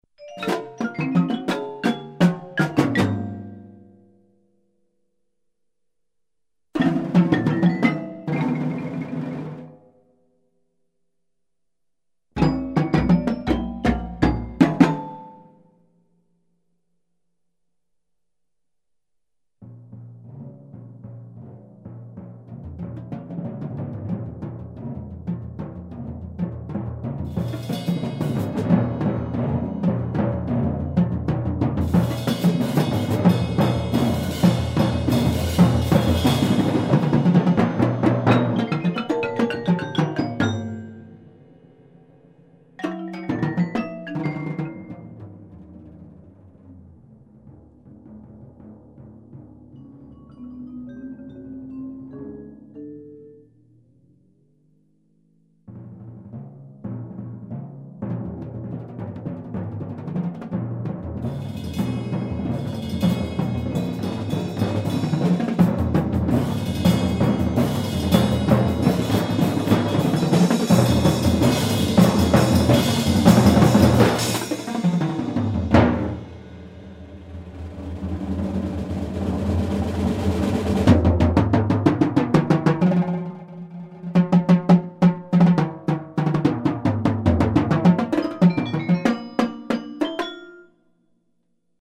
modern classical music for percussion
a demanding and complex work for percussion ensemble